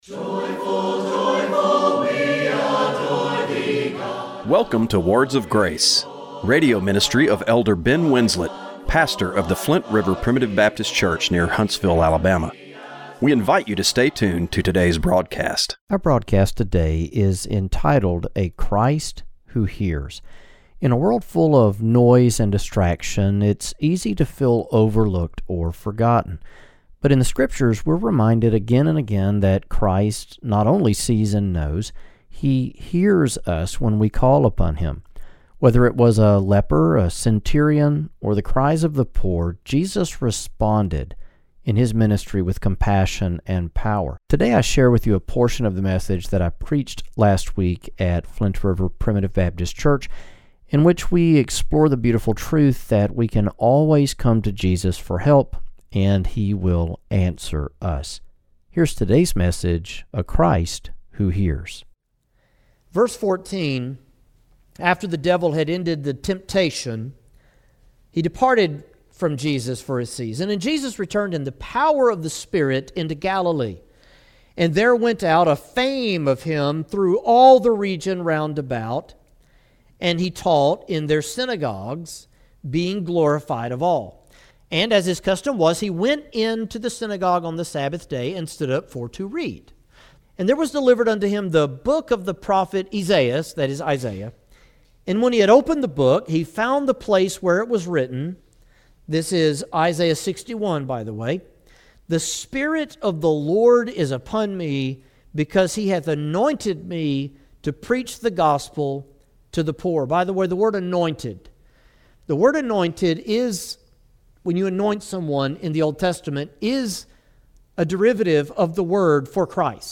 Radio broadcast for August 3, 2025.